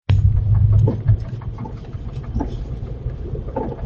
Rapid knocking noise when car warmed up
Here is a recording of it - but done with a phone and not great, however it shows that the knocking is about 7.6 per second and speed would be very appx 40-50 mph
In the recording there is a also a slower "walloping" sound very appx every second - unknown origin and not the noise she is concerned about) Can anyone suggest what the fast knocking might be?